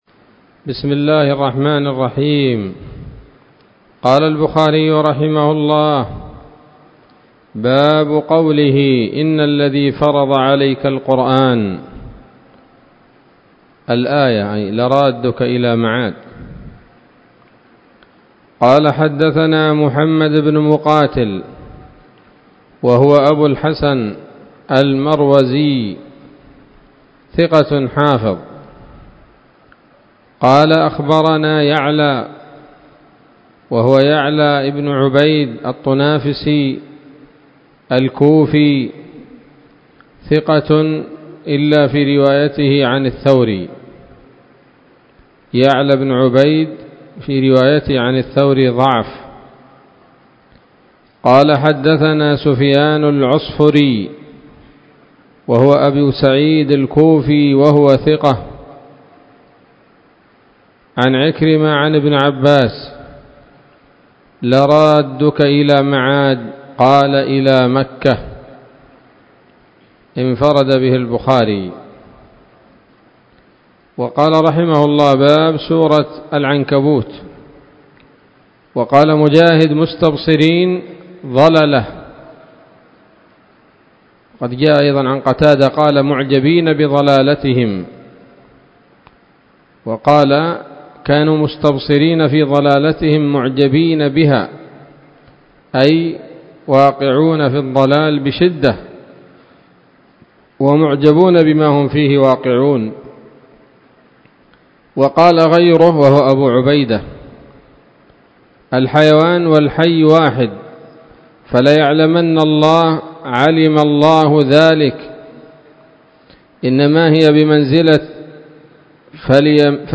الدرس السادس والتسعون بعد المائة من كتاب التفسير من صحيح الإمام البخاري